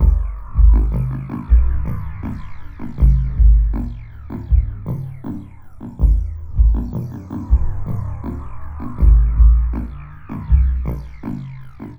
Downtempo 15.wav